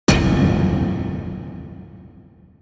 impact-3.mp3